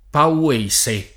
pauese [ pau- %S e ]